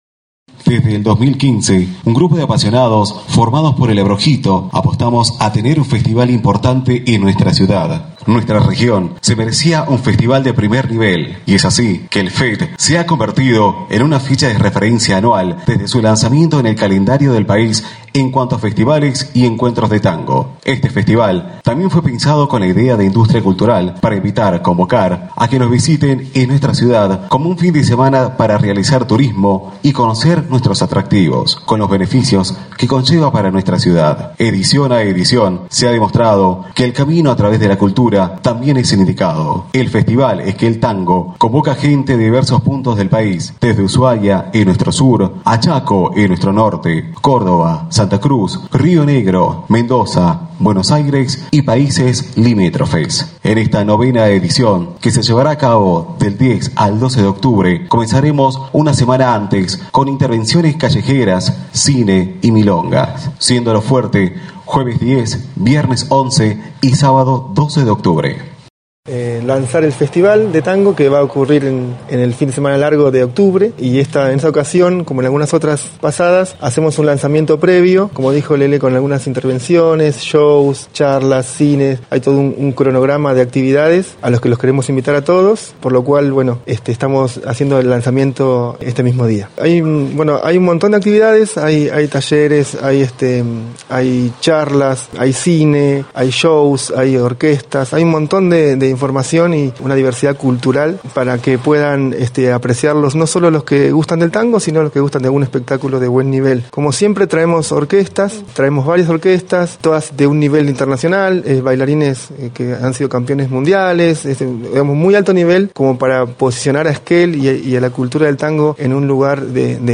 En conferencia de prensa fue presentada la 9° edición del FET (Festival Esquel Tango) que se realizará entre el 10 y el 12 de octubre en la Sociedad Española. La previa del Festival incluirá charlas, música y danzas al aire libre y también en locales comerciales.